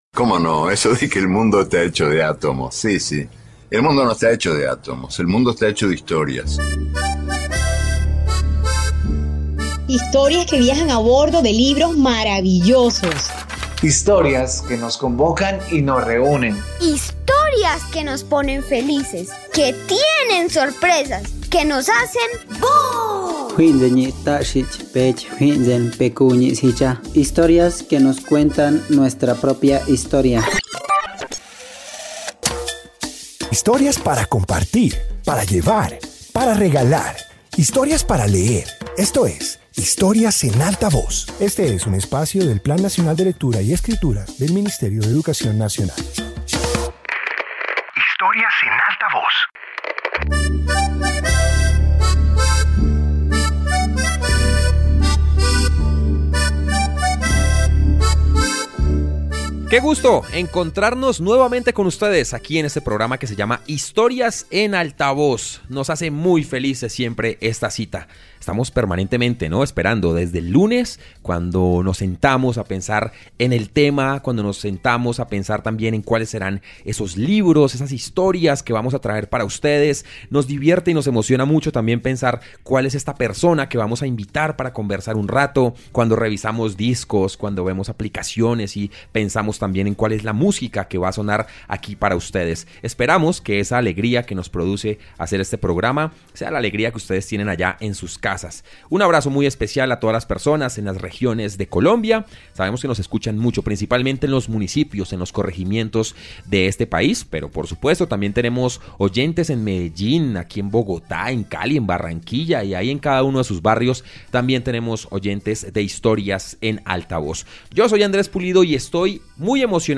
Introducción Este episodio de radio comparte historias que reflejan la riqueza cultural del continente africano. Presenta narraciones sobre memoria, tradición y legado, mostrando su diversidad y su importancia para la humanidad.